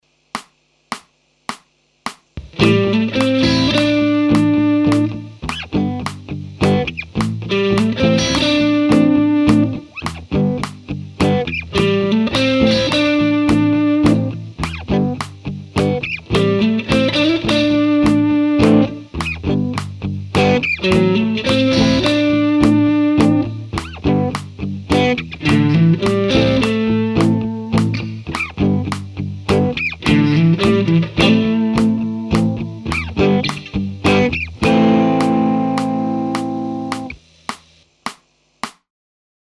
Below is a single Rock/Blues lick that is reworked 6 times using a different approach each time.
It keeps your rhythms from feeling too predictable and adds a funky, syncopated feel.
Example 3: Here, the lick is played with swing rhythm.
Example 5: Another subtle change – the starting note here has shifted down a fret.
Varying-the-same-lick-improv-lesson.mp3